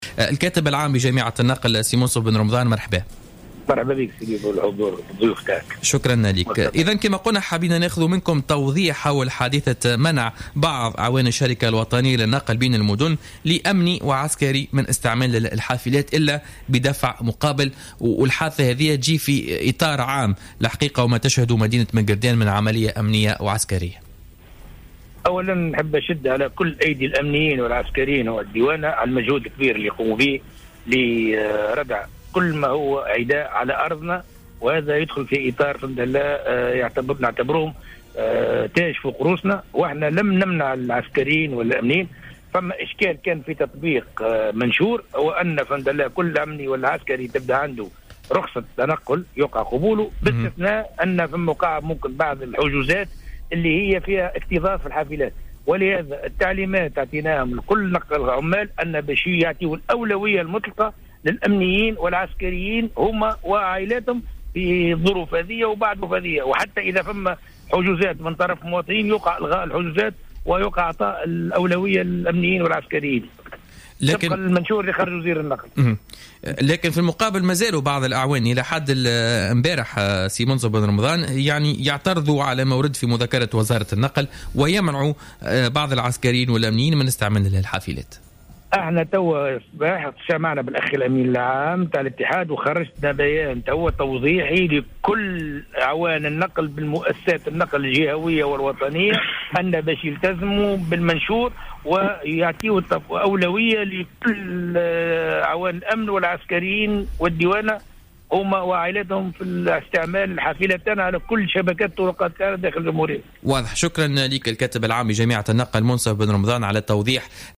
تصريح للجوهرة أف أم في برنامج بوليتكا